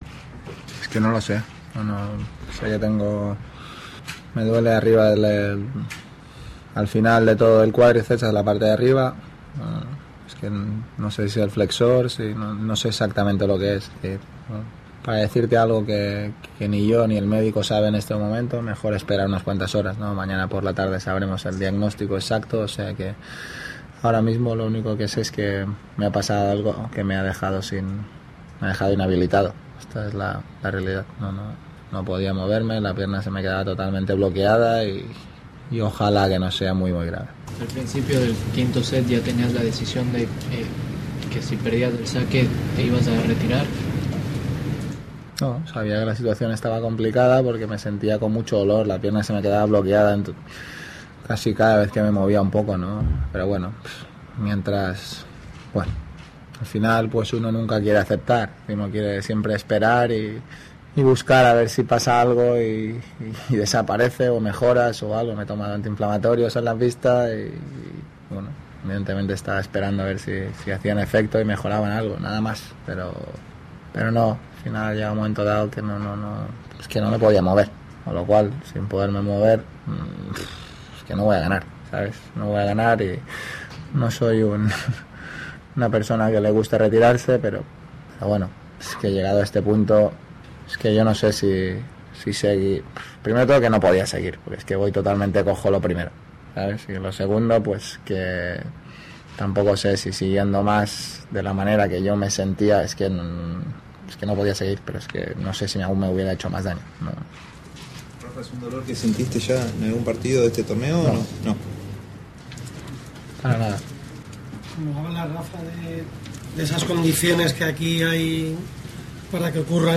Rafael Nadal durante la rueda de prensa, en el Abierto de Australia.
Escucha arriba en nuestro podcast la entrevista en Español.